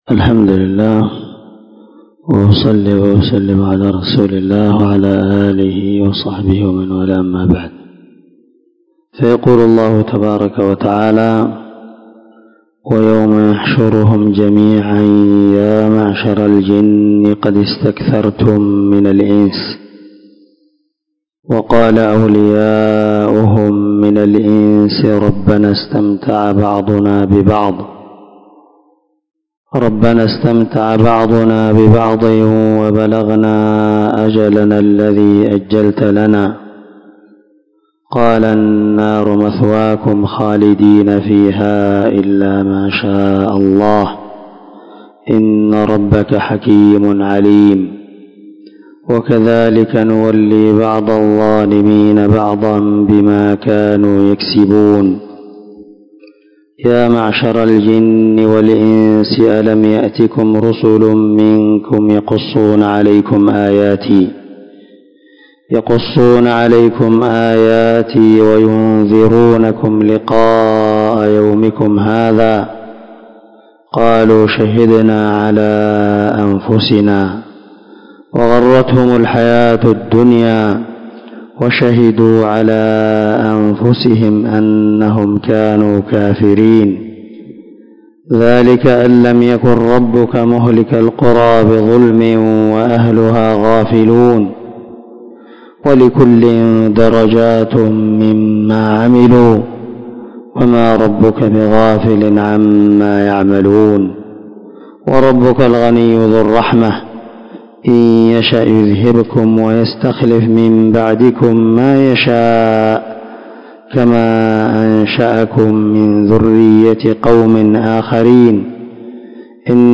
435الدرس 43 تفسير آية ( 128 - 135 ) من سورة الأنعام من تفسير القران الكريم مع قراءة لتفسير السعدي